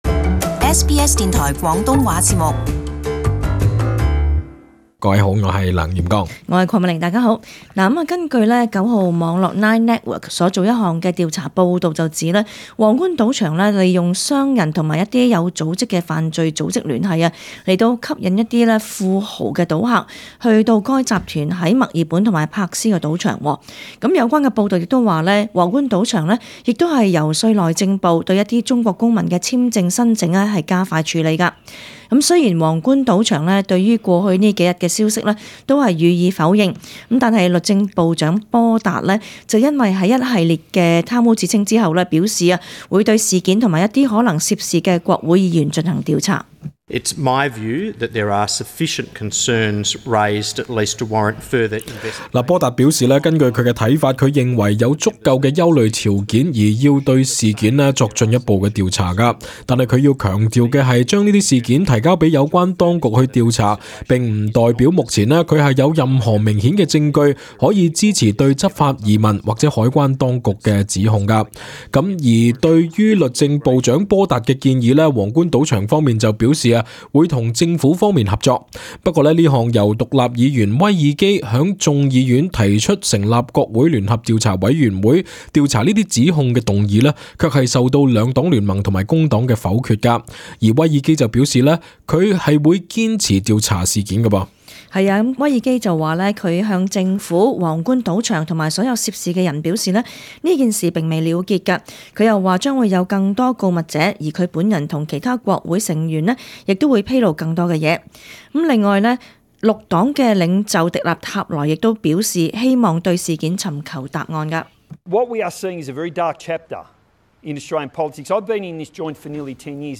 Source: AAP SBS广东话播客 View Podcast Series Follow and Subscribe Apple Podcasts YouTube Spotify Download (8.2MB) Download the SBS Audio app Available on iOS and Android 联邦政府表示会将对皇冠赌场的贪污及涉及不当行为的指控提交澳洲执法廉政委员会调查。